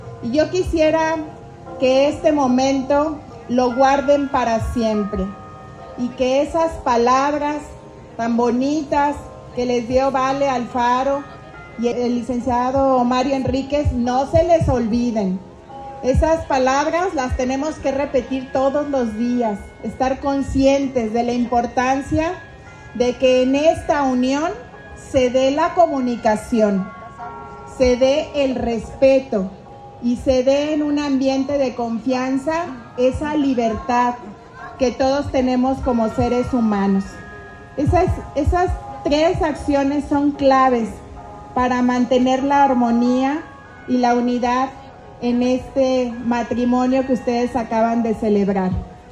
AudioBoletines
Lorena Alfaro García, presidenta municipal